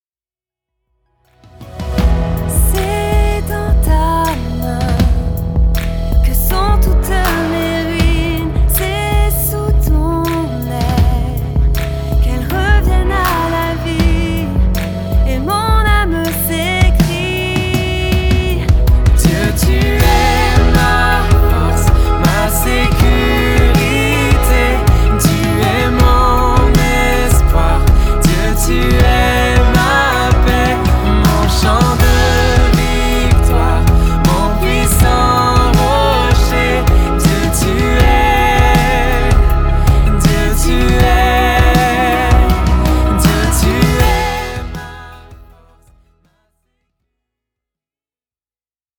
chants de louange aux accents électro